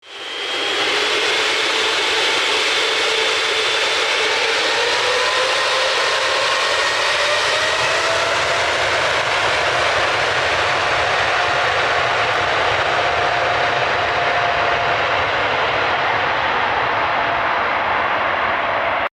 На этой странице собраны реалистичные звуки двигателя самолета: от плавного гула турбин до рева при взлете.
Турбина пассажирского реактивного самолета